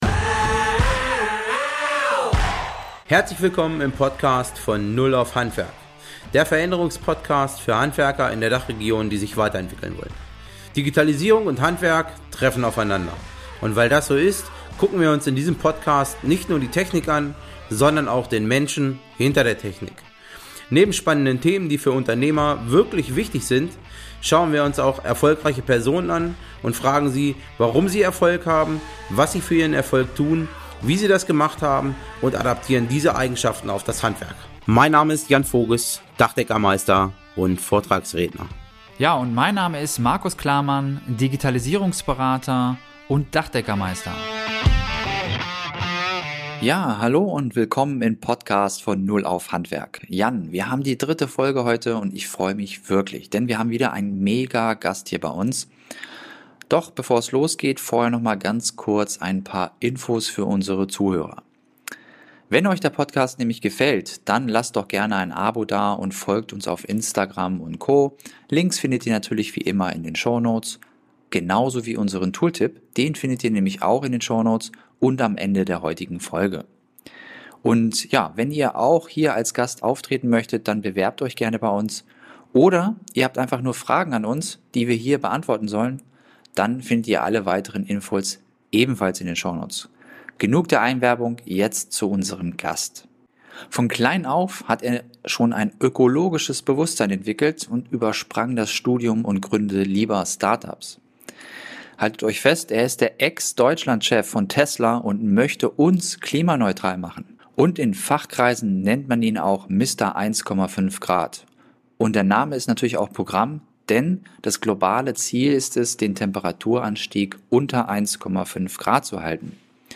In dieser Folge sprechen wir mit einem deutschen Top-Manager, Gründer und Unternehmer aus der new Economy.